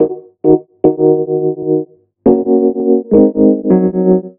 RI KEYS 1 -L.wav